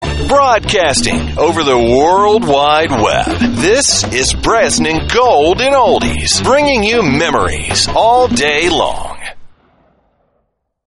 Radio Imaging & Voiceover